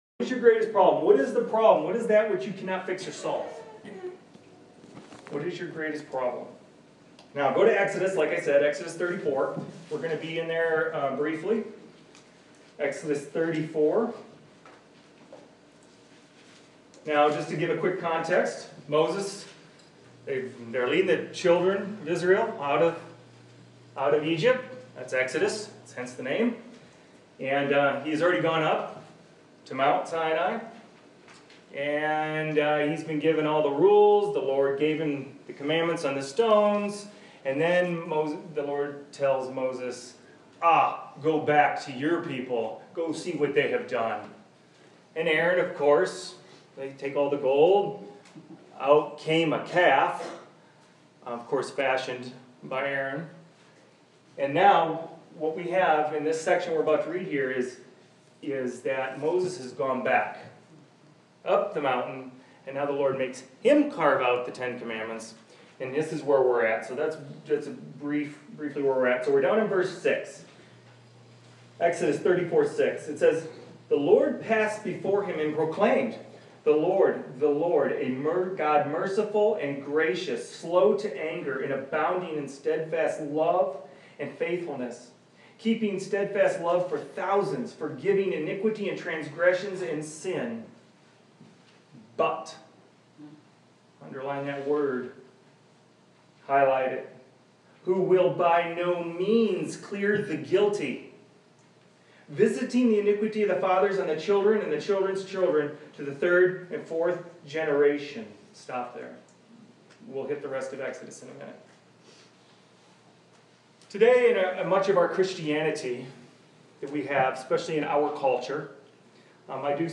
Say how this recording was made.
Starts just after the introduction.